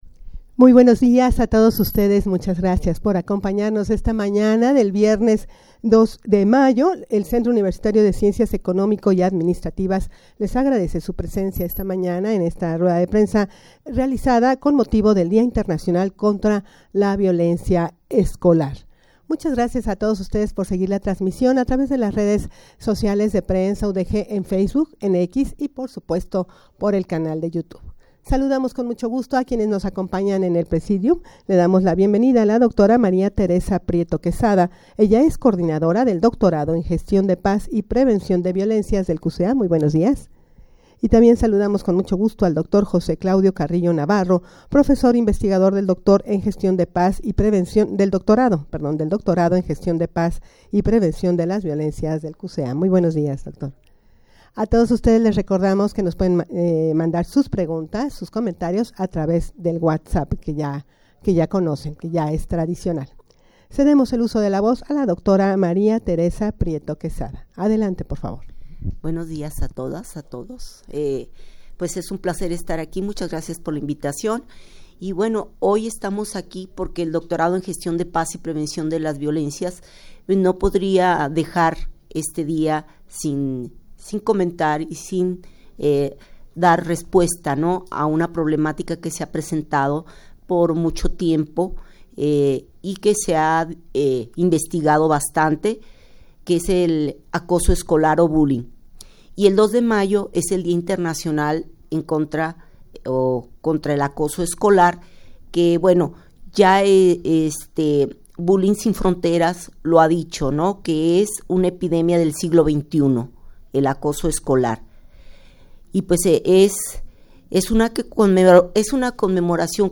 Audio de la Rueda de Prensa
rueda-de-prensa-con-motivo-del-dia-internacional-contra-la-violencia-escolar.mp3